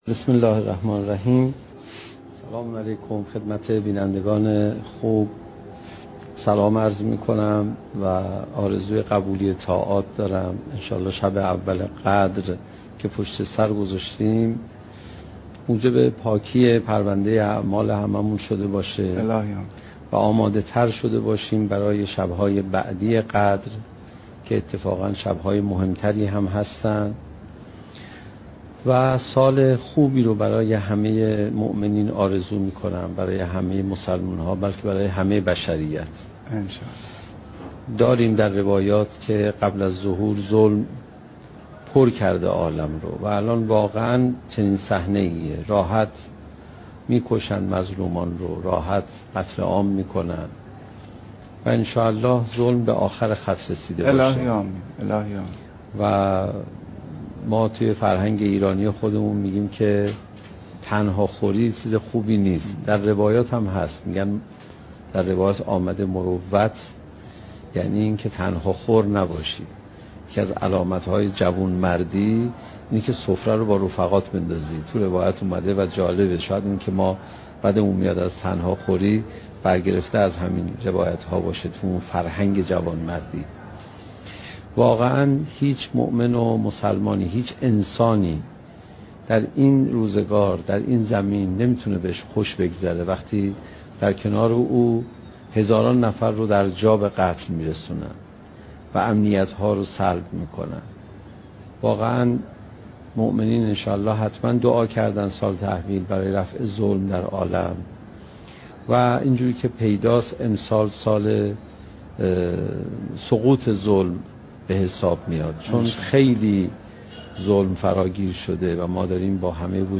سخنرانی حجت الاسلام علیرضا پناهیان با موضوع "چگونه بهتر قرآن بخوانیم؟"؛ جلسه هجدهم: "نتایج ذکر قرآن"